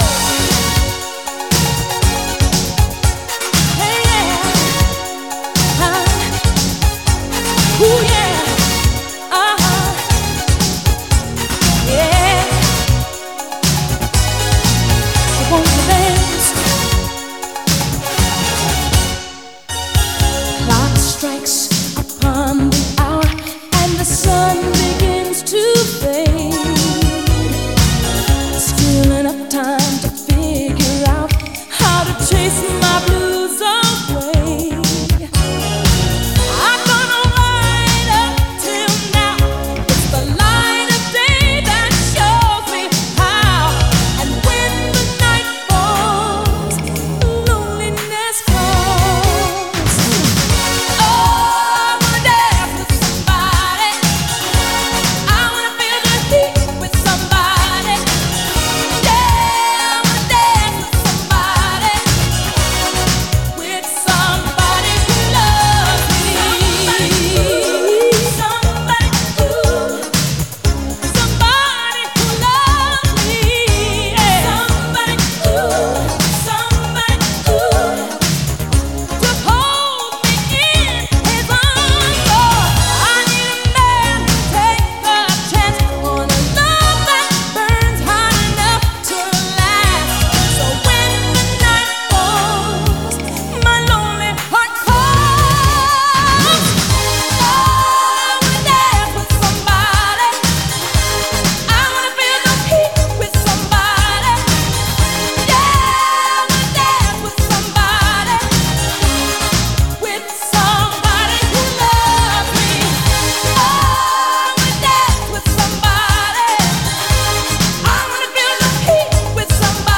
BPM119
Audio QualityLine Out